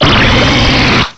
cry_not_goodra.aif